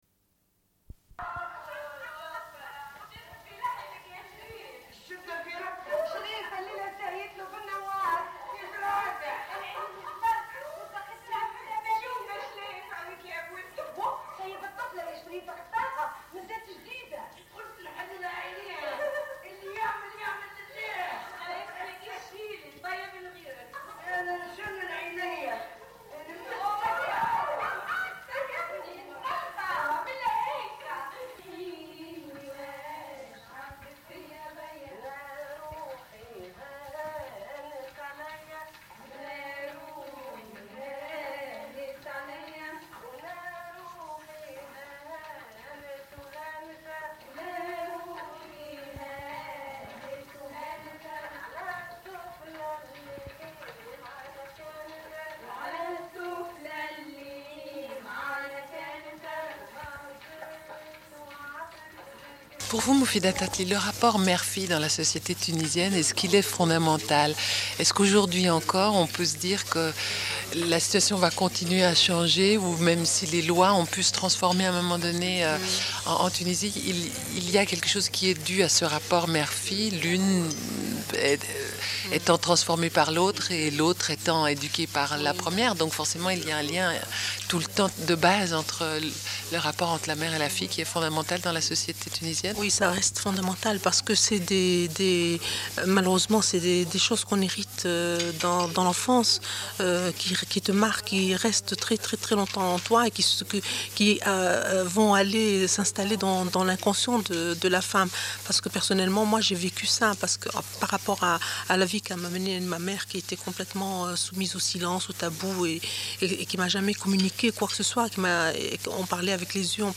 Sommaire de l'émission : au sujet du film Les silence du palais de Moufida Tatli, réalisatrice tunisienne. Diffusion d'un entretien avec elle (malheureusement une moitié de l'entretien est manquante pour des raisons techniques, comme l'explique l'animatrice).
Une cassette audio, face B